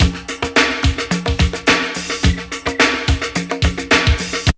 ZG2BREAK10#9.wav